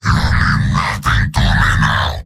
Giant Robot lines from MvM. This is an audio clip from the game Team Fortress 2 .
Heavy_mvm_m_revenge01.mp3